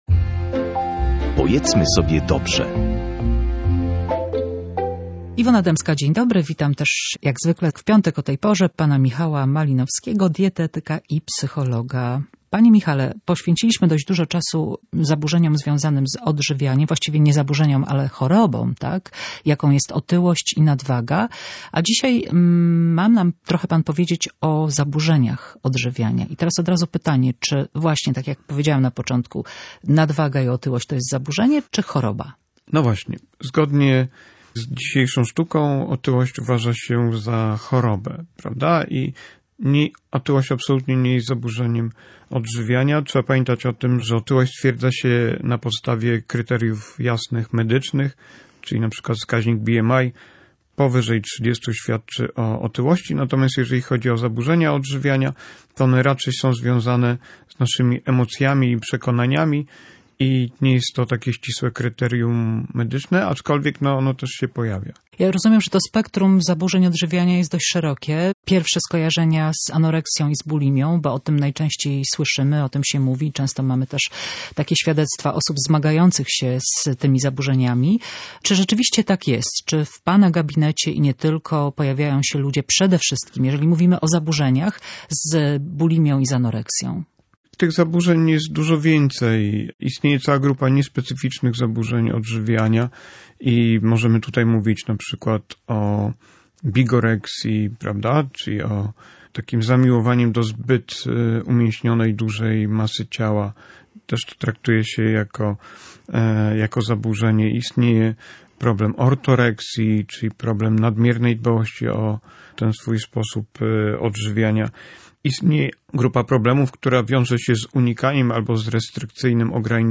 dietetyk i psycholog
w rozmowie